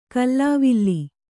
♪ kallāvilli